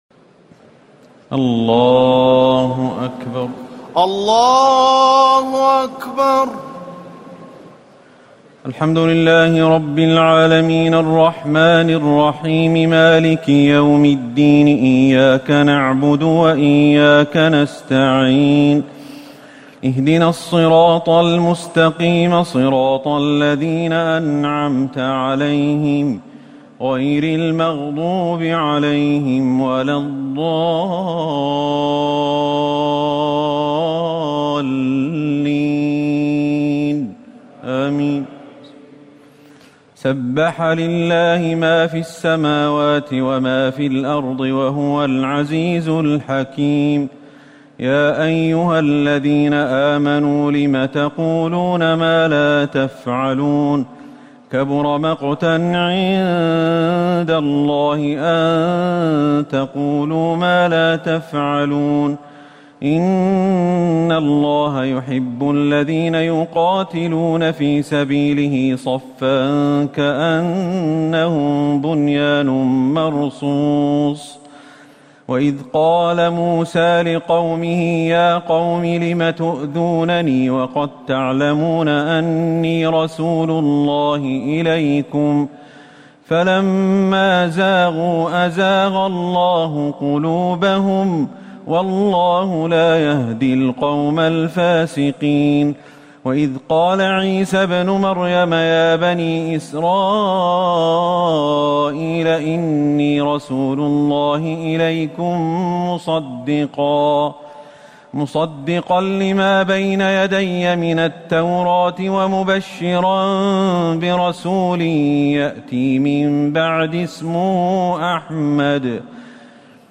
تراويح ليلة 27 رمضان 1439هـ من سورة الصف الى التحريم Taraweeh 27 st night Ramadan 1439H from Surah As-Saff to At-Tahrim > تراويح الحرم النبوي عام 1439 🕌 > التراويح - تلاوات الحرمين